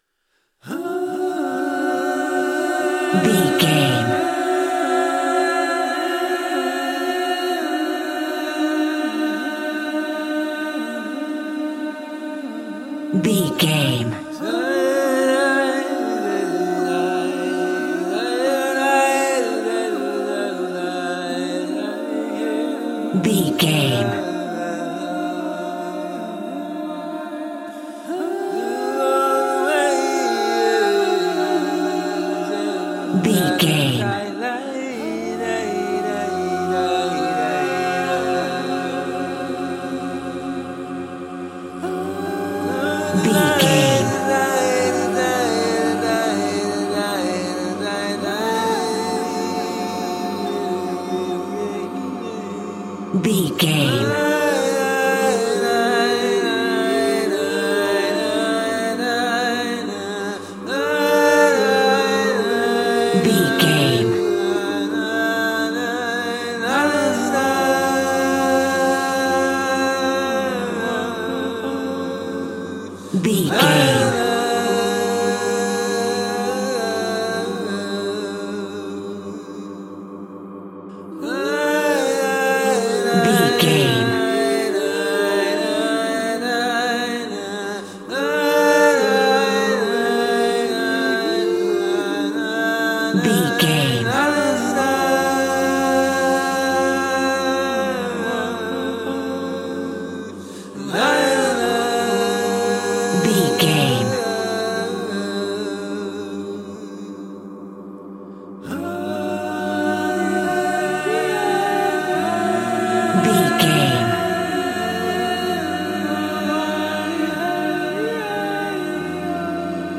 Aeolian/Minor
Slow
relaxed
tranquil
synthesiser
drum machine